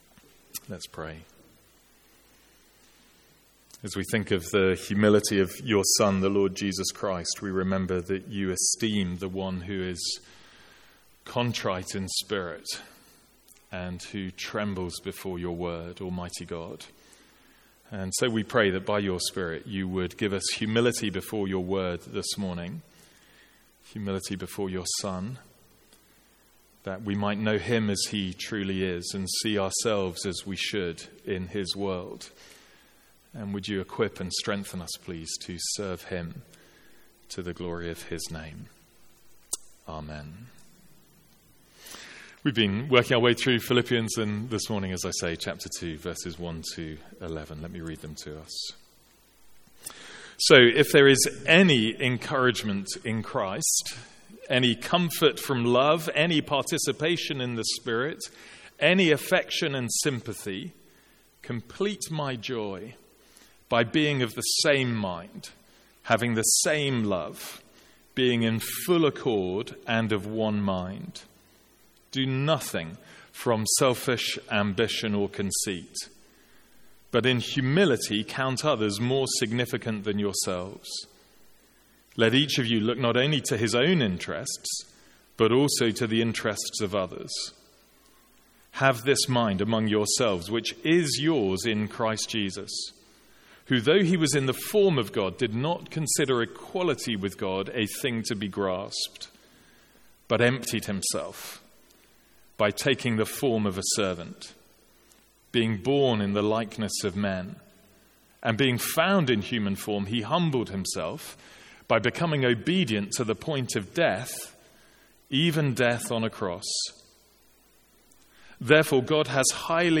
Sermons | St Andrews Free Church
From our morning series in Philippians.